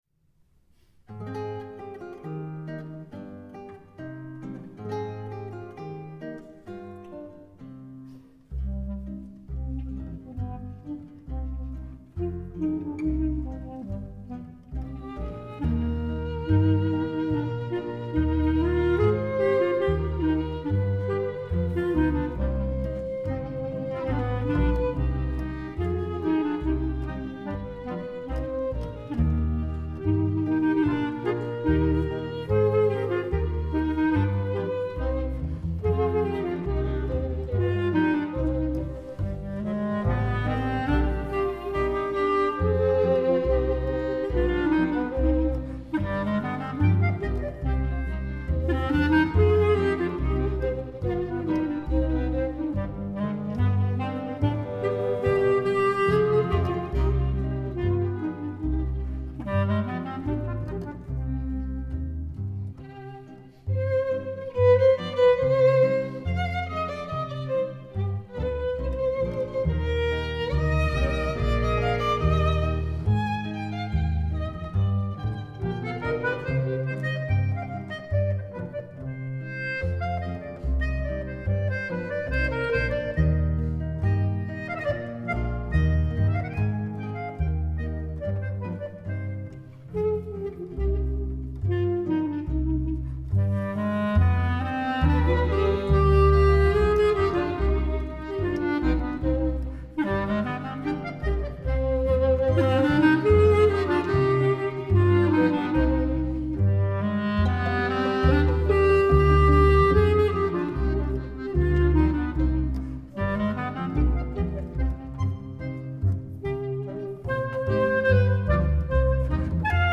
кларнет
Genre: Folk, World,